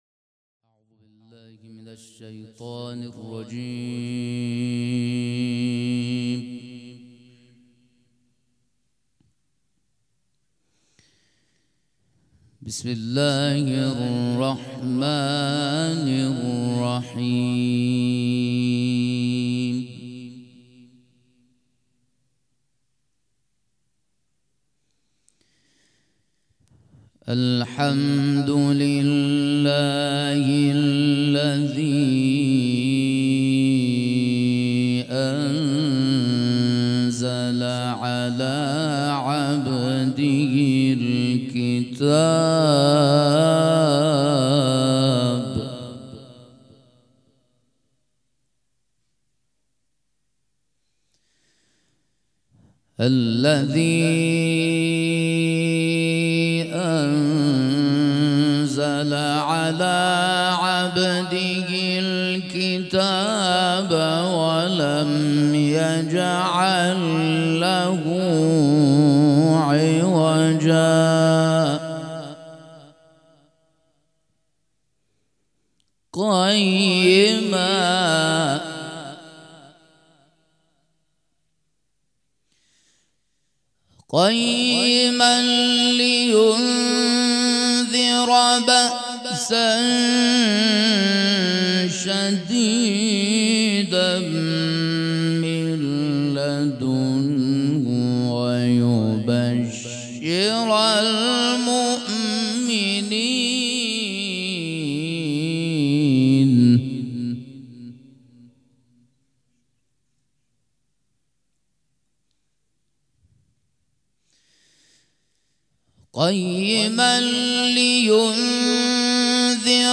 قرائت قرآن
مراسم عزاداری شب سوم